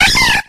infinitefusion-e18 / Audio / SE / Cries / HOPPIP.ogg